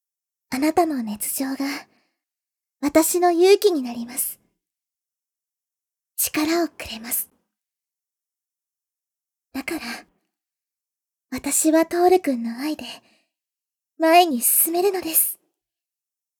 ココロいちゃらぶボイス | PandoraPartyProject
ボイス種別 提案ボイス